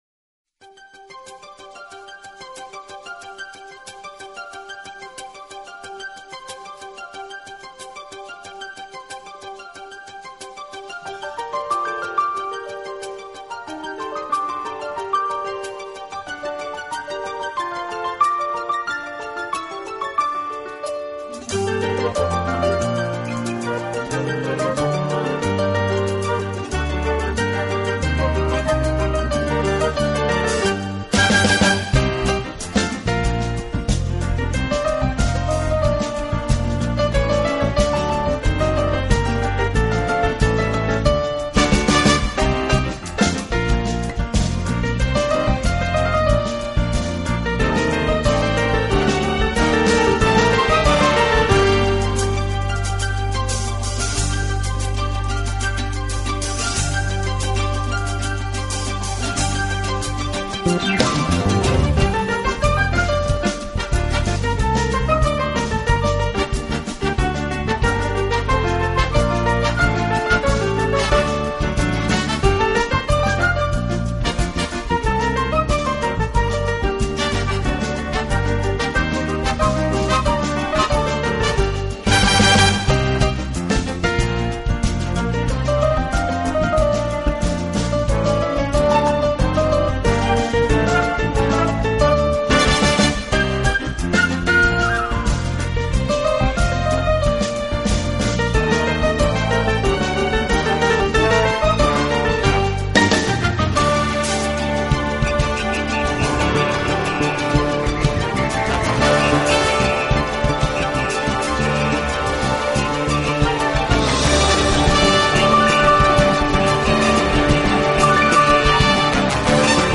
【拉丁钢琴】